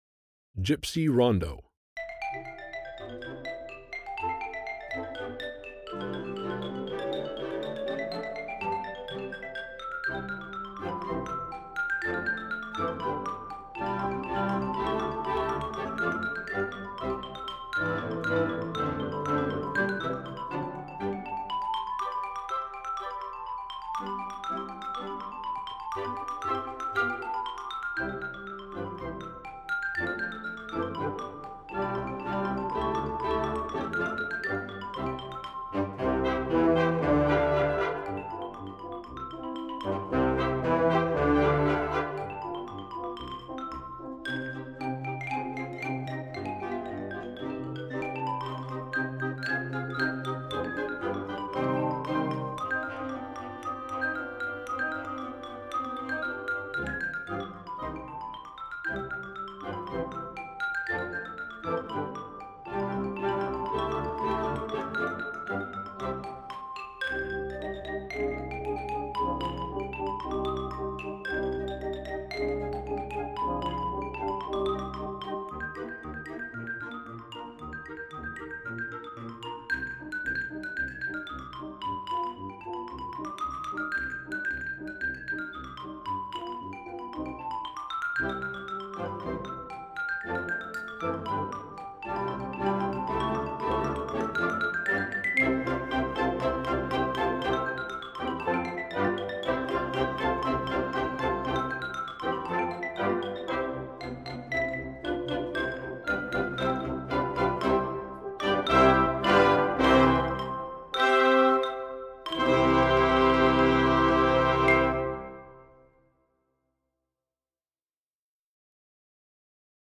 Voicing: Xylophone w/ Band